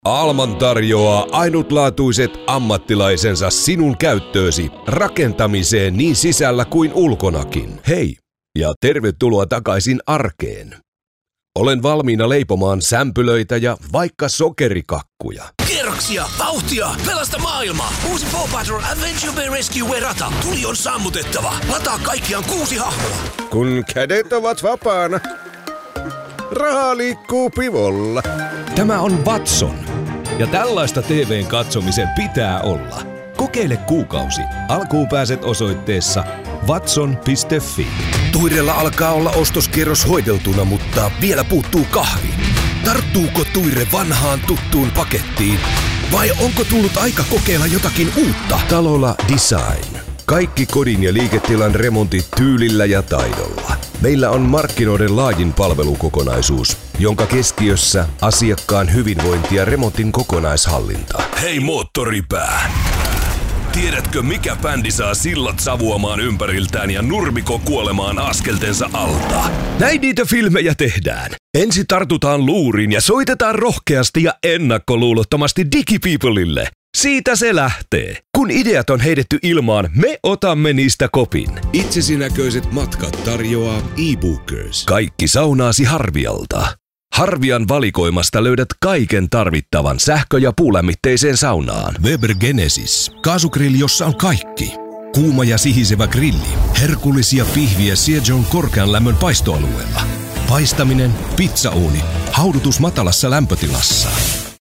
Promos
announcer, authoritative, Booming, cool, Deep Voice, edgy, foreign-language, hard-sell, high-energy, promo, tough, upbeat, worldly